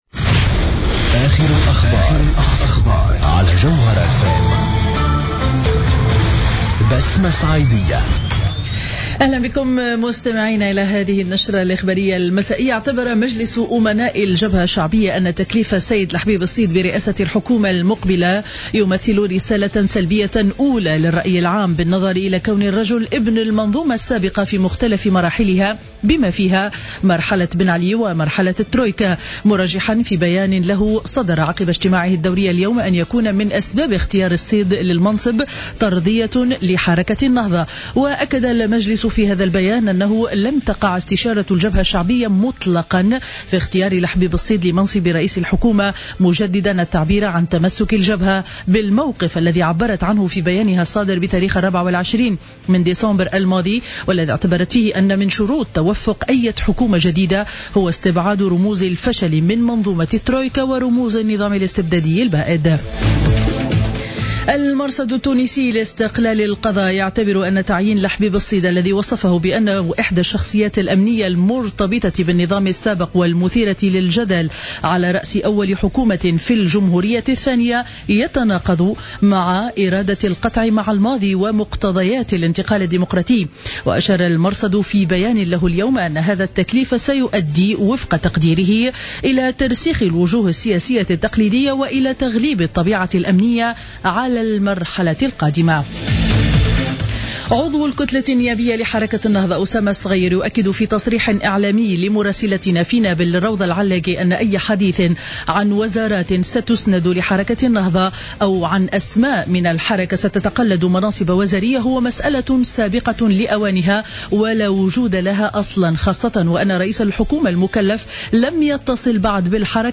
نشرة أخبار السابعة مساء ليوم الثلاثاء 06-01-15